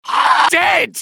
medic_paincrticialdeath04.mp3